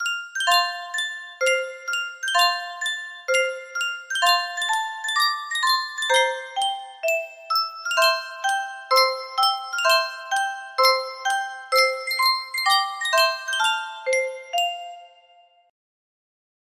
Sankyo Music Box - Polly Wolly Doodle 5D music box melody
Full range 60